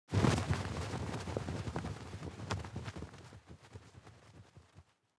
دانلود صدای پرنده 53 از ساعد نیوز با لینک مستقیم و کیفیت بالا
جلوه های صوتی